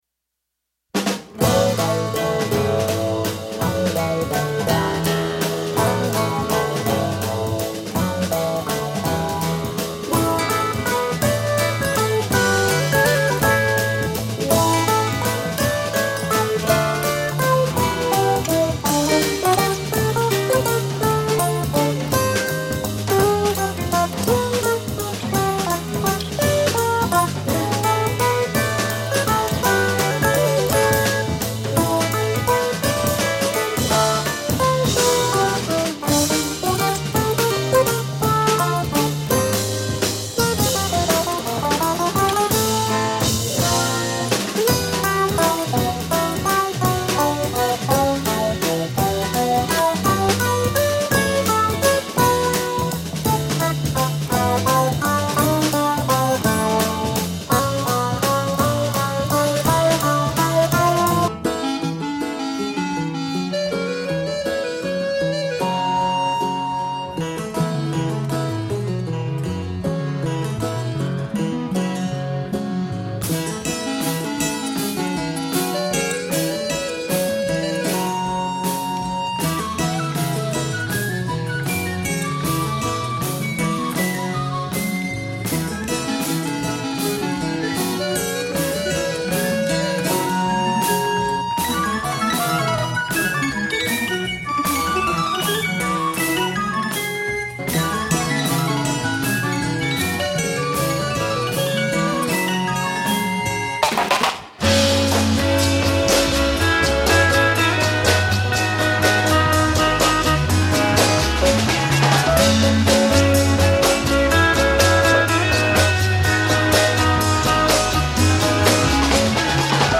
Today’s explorations will lean toward jazz.